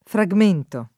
frammento [ framm % nto ] s. m.